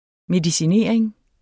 Udtale [ medisiˈneˀɐ̯eŋ ]